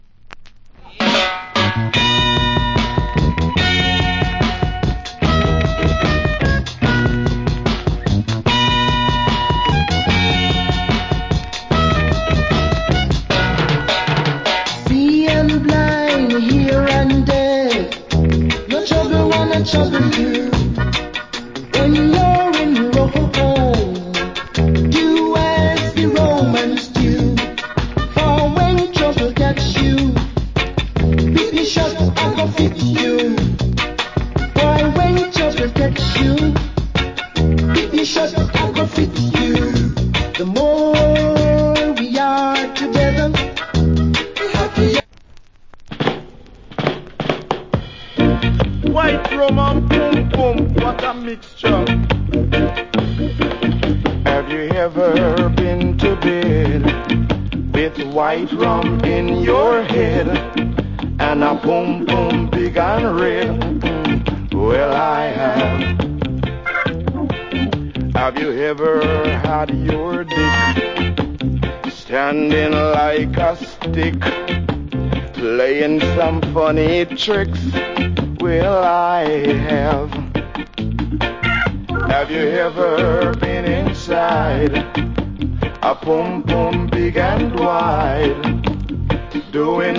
Good Reggae Vocal.